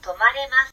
to ma re ma su